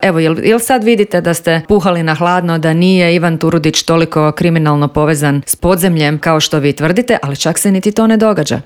ZAGREB - Prijepori oko Ivana Turudića i procedure izbora glavnog državnog odvjetnika, izmjene Kaznenog zakona, veliki prosvjed oporbe, sindikalni prosvjedi i potencijalni štrajkovi neke su od tema o kojima smo u Intervjuu Media servisa razgovarali sa saborskom zastupnicom iz Stranke s imenom i prezimenom Dalijom Orešković.